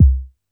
Kicks
07_Kick_18_SP.wav